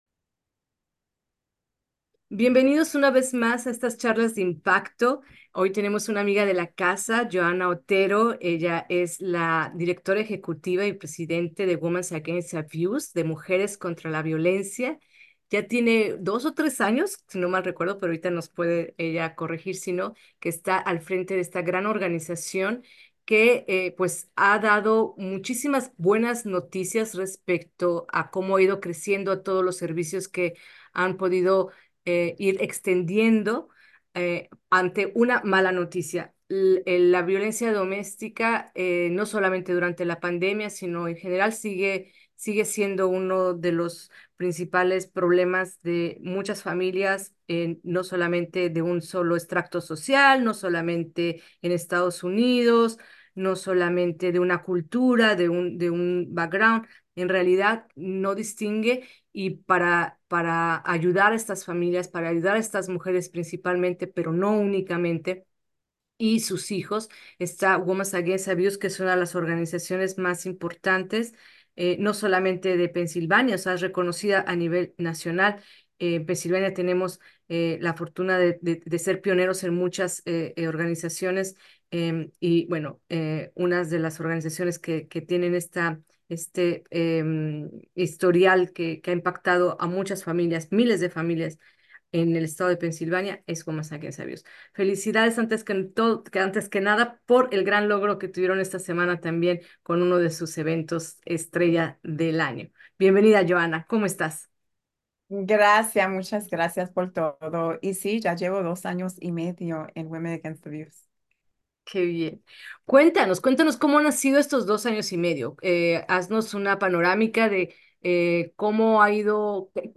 La violencia doméstica es un problema que no distingue entre clases sociales o culturas, y afecta a personas de todas las esferas de la sociedad. En entrevista